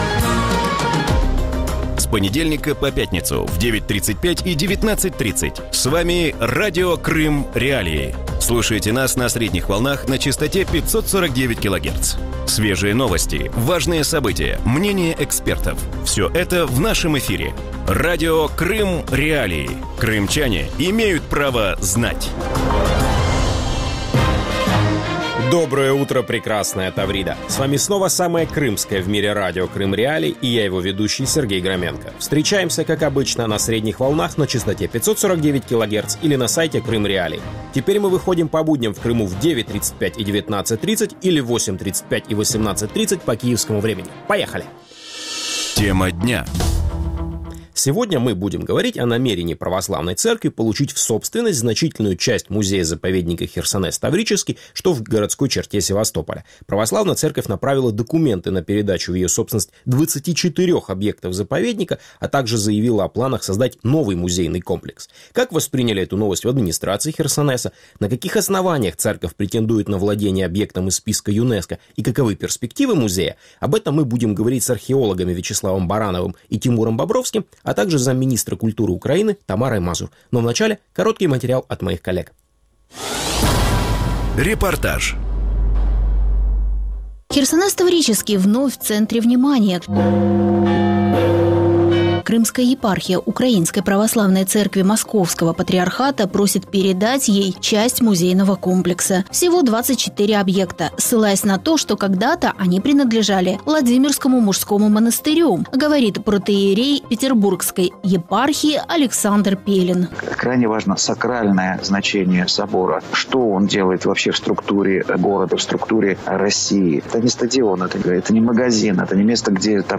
У ранковому ефірі Радіо Крим.Реалії 25 січня говорять про намір Московського патріархату отримати у власність значну частину музею-заповідника «Херсонес Таврійський». Сімферопольська єпархія направила документи на передачу в її власність 24-х об'єктів заповідника, а також заявила про плани створити новий музейний комплекс.